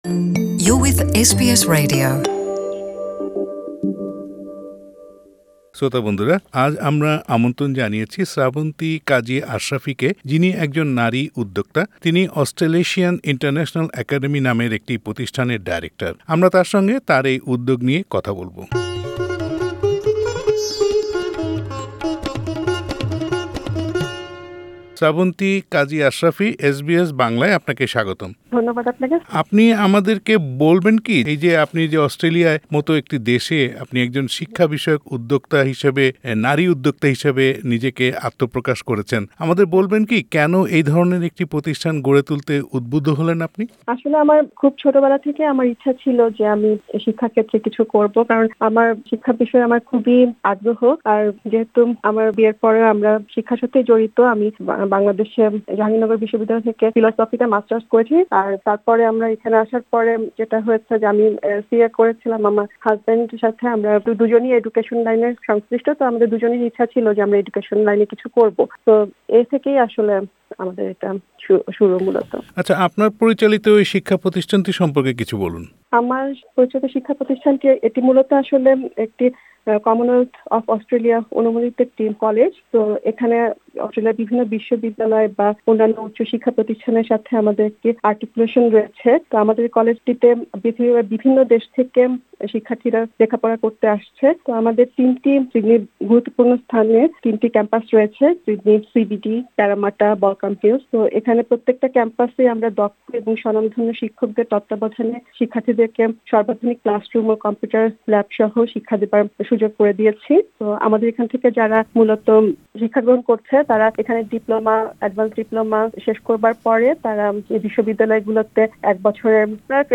এসবিএস বাংলার সঙ্গে কথা বলেছেন তিনি।